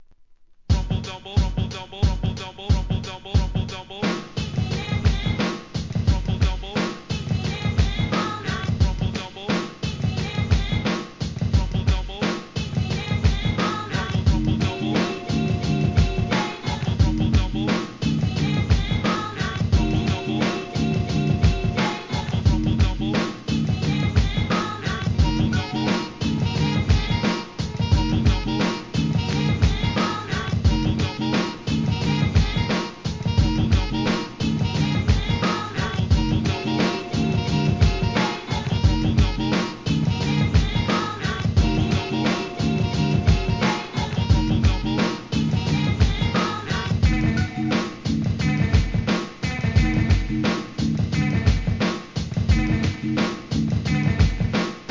1. HIP HOP/R&B
ブレイクビーツ